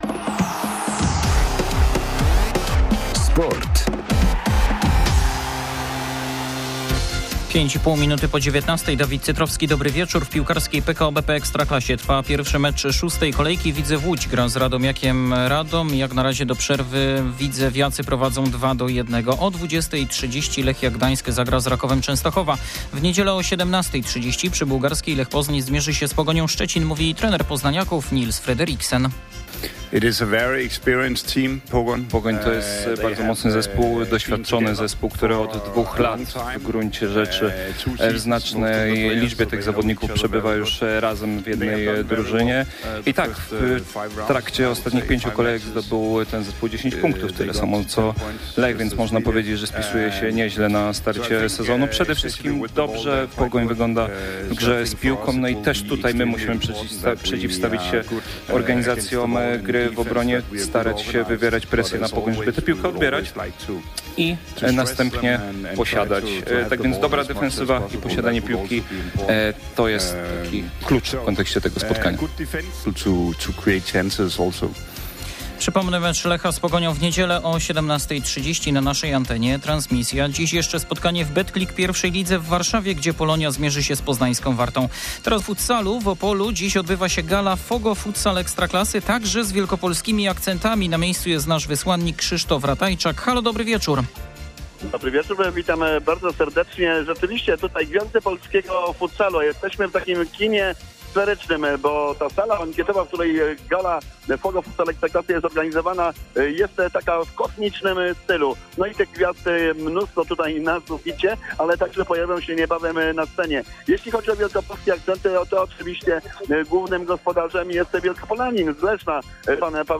23.08.2024 SERWIS SPORTOWY GODZ. 19:05
Jaką druzyną jest Pogoń Szczecin? O tym mówi trener Lecha Niels Frederiksen przed starciem z Portowcami. W naszym serwisie także relacja z gali Futsal Ekstraklasy.